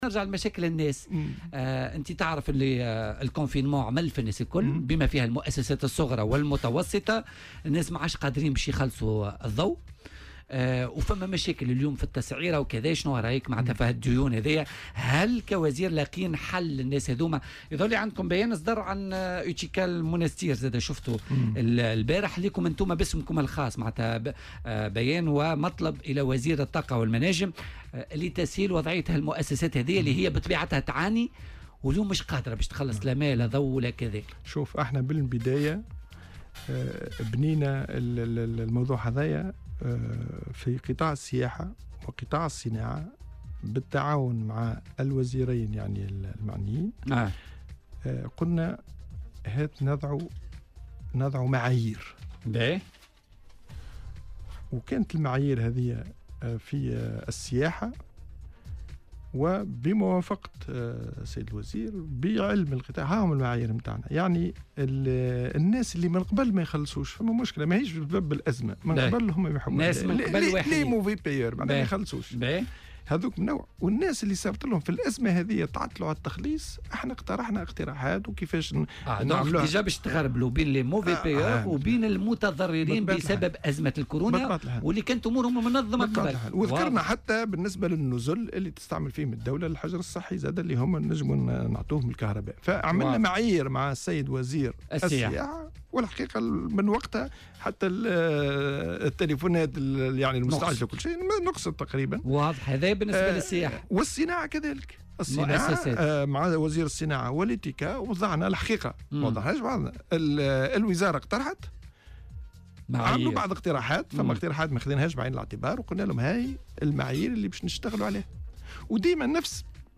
وأضاف في مداخلة له اليوم في برنامج "بوليتيكا" أنه سيتم اتخاذ اجراءات خاصة بأصحاب النزل والمؤسسات الصناعية المتضرّرة من أزمة الكورونا، مشيرا إلى أنه تم وضع معايير واضحة لعملية الخلاص ( تأجيل عملية الخلاص لا تشمل الديون المتخلّدة السابقة بل الديون المتعلقة بجائحة كورونا).